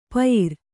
♪ payir